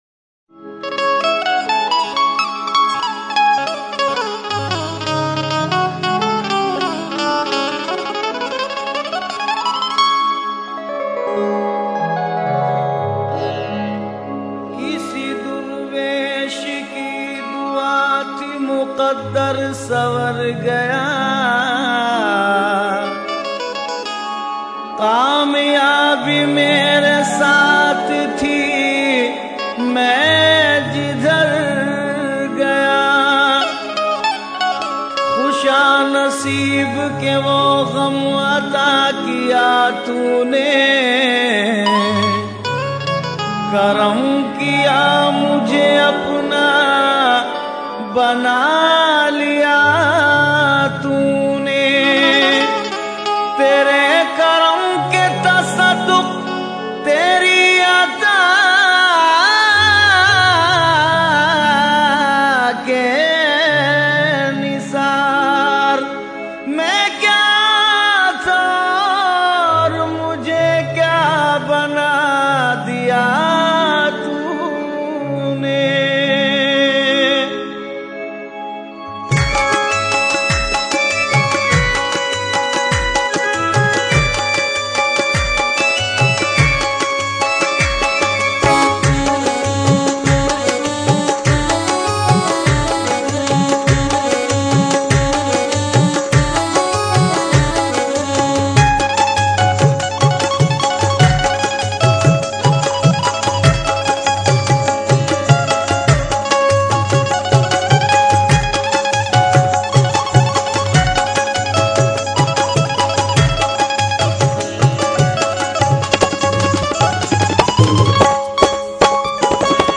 Islamic Qawwalies And Naats > Dargahon Ki Qawwaliyan